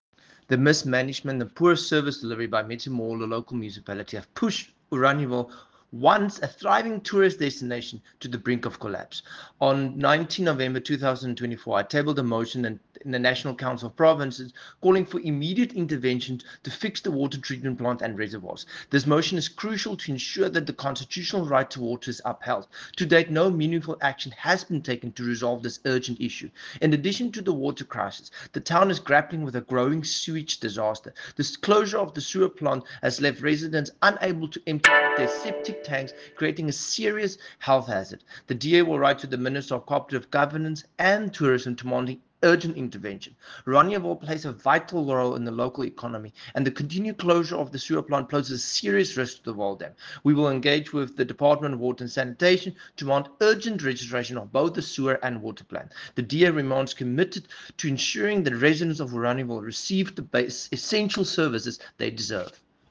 Afrikaans soundbites by Dr Igor Scheurkogel MP and
Sewearge-crisis-in-Metsimaholo-ENG.mp3